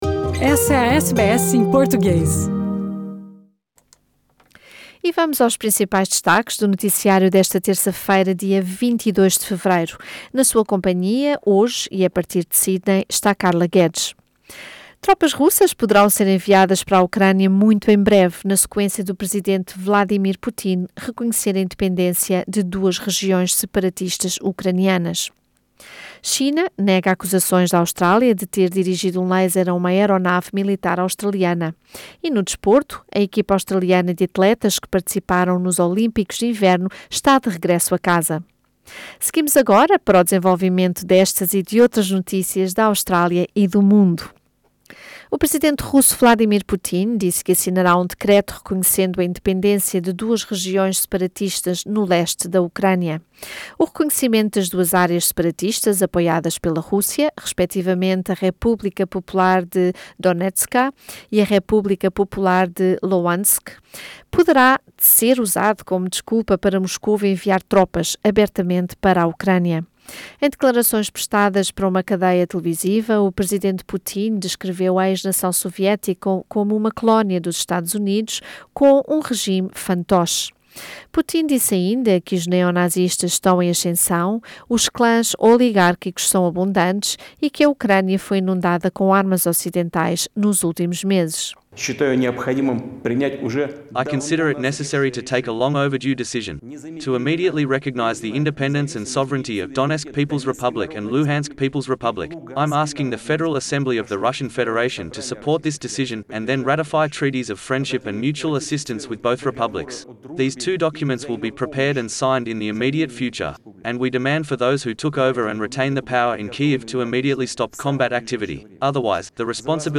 Tropas russas poderão ser enviadas para a Ucrânia muito em breve, na sequência do presidente Vladimir Putin reconhecer a independência de regiões separatistas ucranianas. Já a China nega acusações da Austrália de ter dirigido um laser a uma aeronave militar australiana. E no desporto, a equipa australiana de atletas que participaram nos Olímpicos de inverno está de regresso a casa. São estas, entre outras, as notícias da Austrália e do mundo da Rádio SBS para esta terça-feira, 22 de fevereiro.